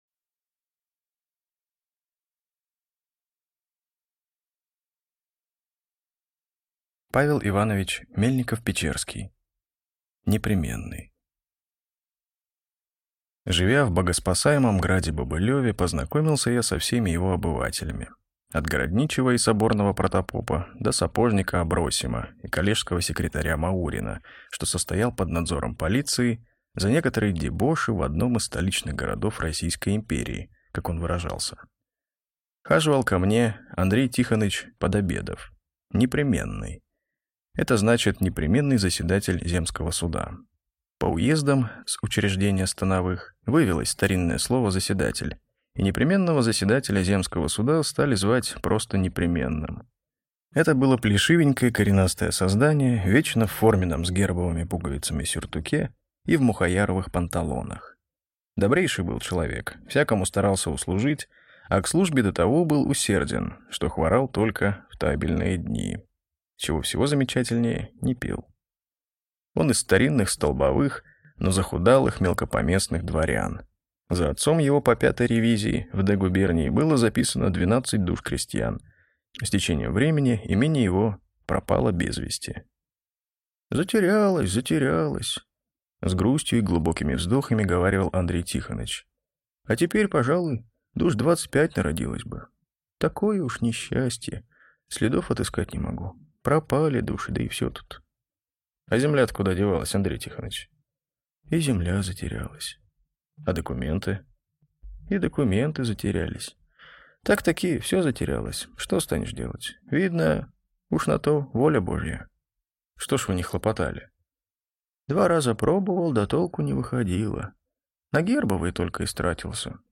Аудиокнига Непременный | Библиотека аудиокниг